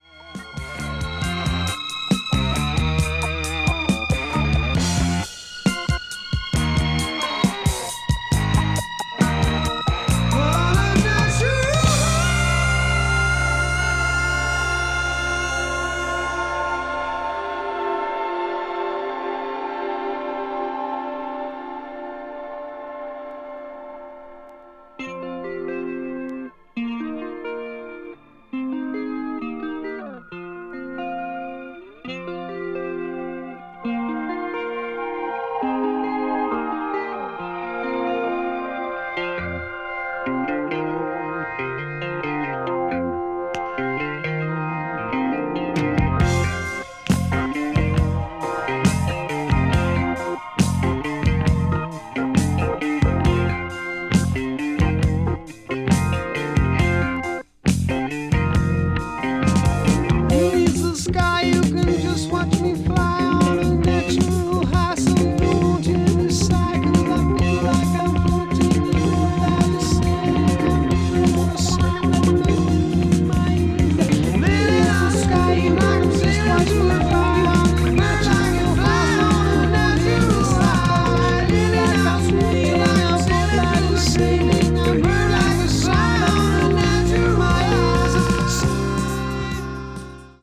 media : EX+/EX+(わずかにチリノイズが入る箇所あり)
art rock   electronic   progressive rock   synmthesizer